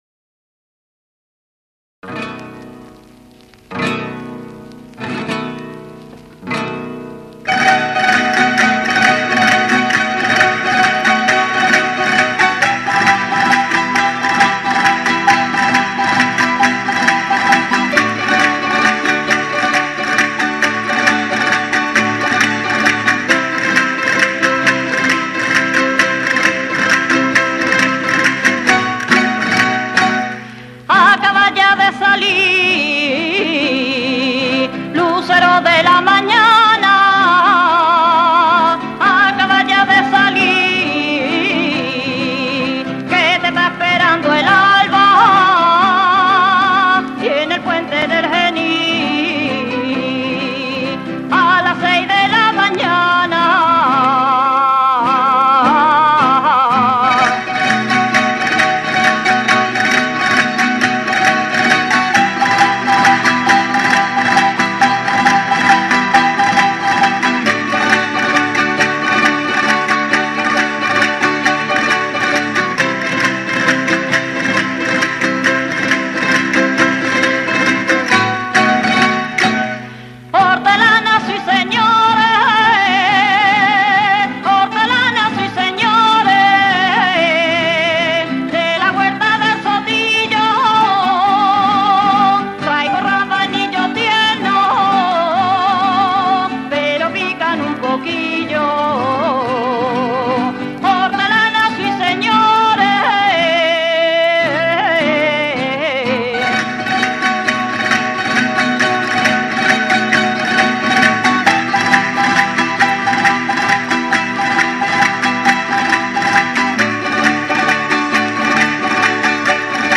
Son muchas las formas flamencas en las que el maestro dejó huella creativa, y una de ellas sin duda es en esta tipología de fandango abandolao.
Primer-y-Segundo-Zangano-de-Puente-Genil-de-Coros-y-Danzas.mp3